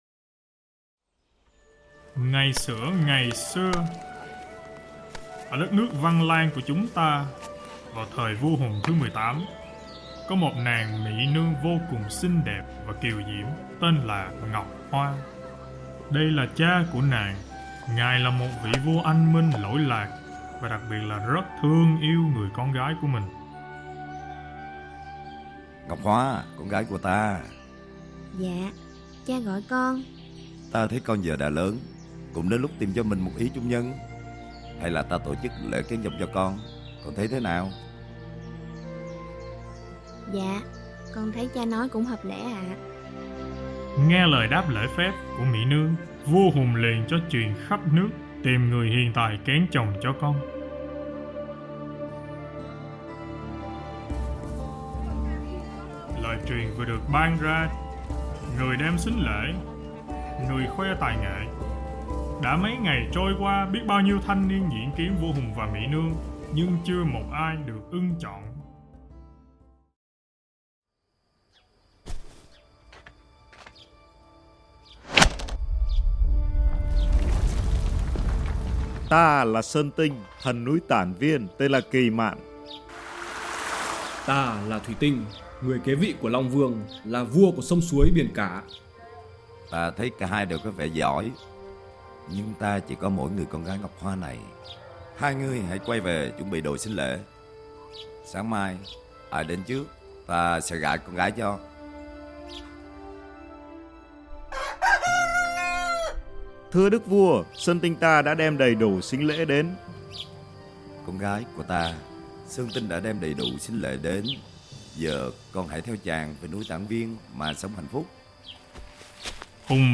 Sách nói | Sơn Tinh Thuỷ Tinh